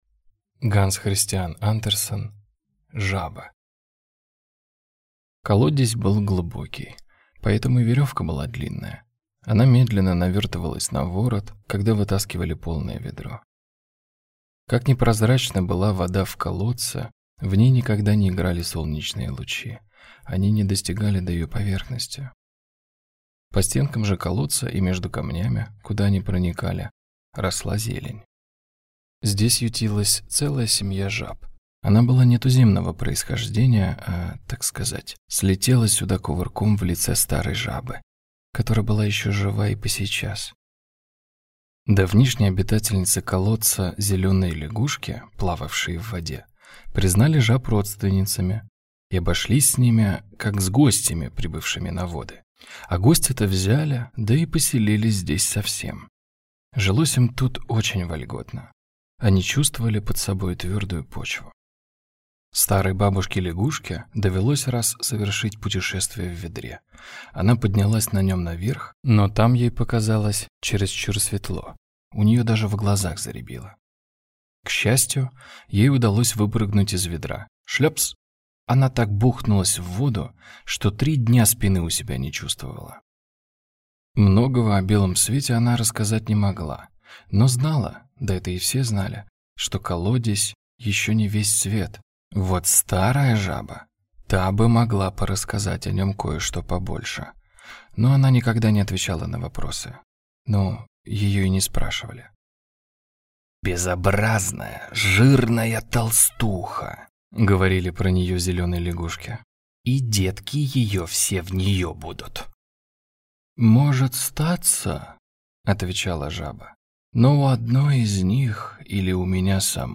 Аудиокнига Жаба | Библиотека аудиокниг